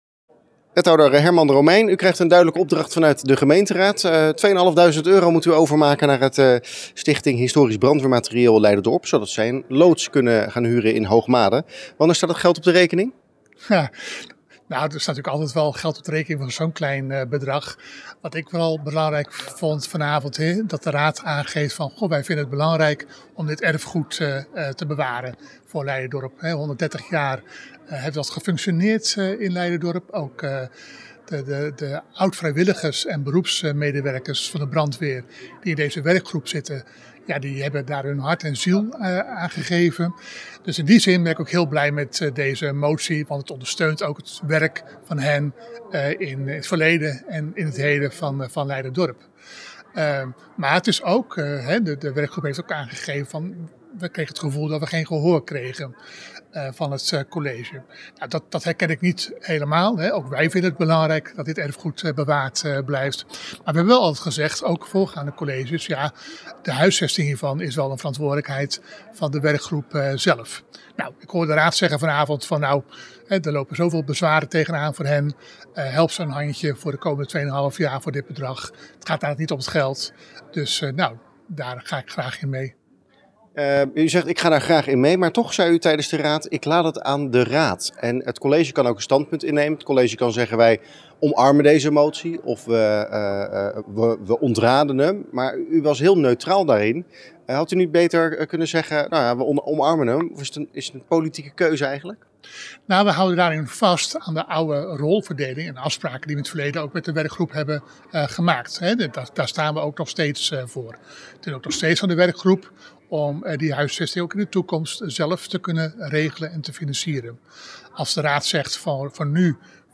Wethouder Hermand Romeijn over de motie: